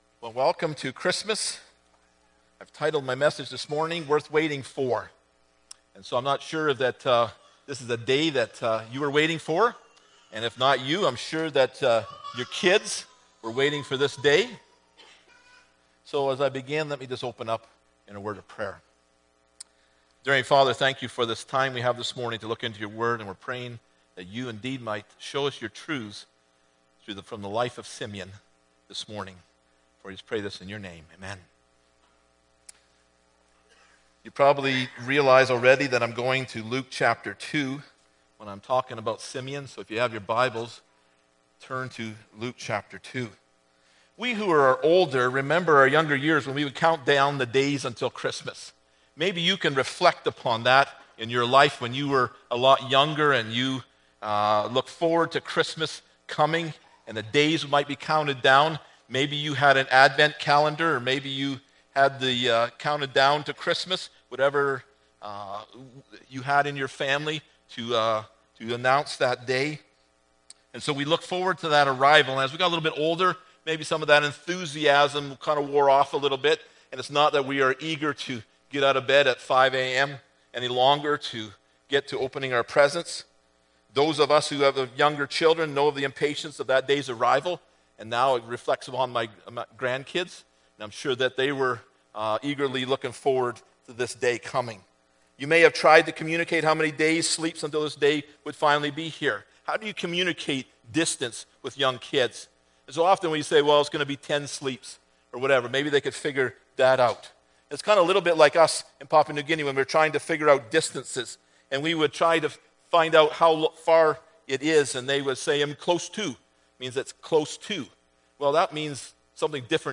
Luke 2:25-35 Service Type: Sunday Morning Bible Text